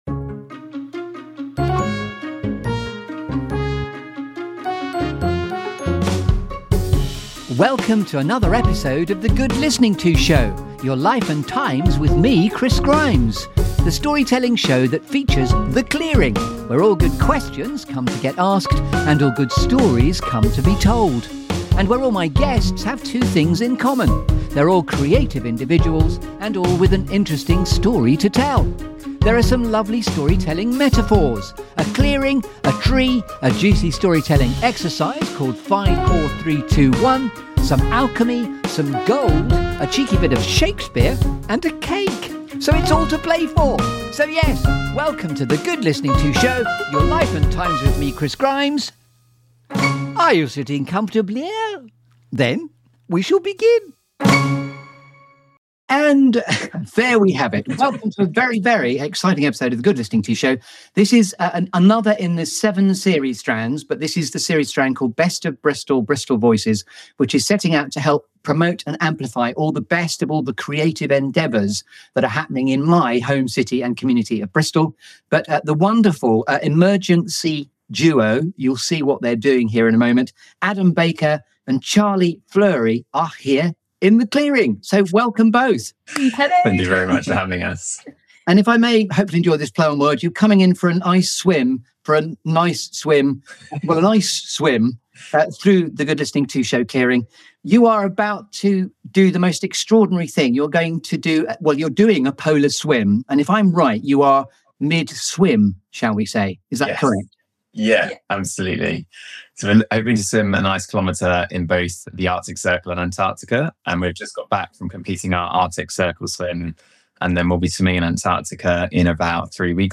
This feel-good Storytelling Show that brings you ‘The Clearing’.
Think Stories rather than Music!